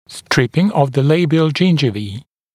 [‘стрипин ов зэ ‘лэйбиэл ‘джиндживи:]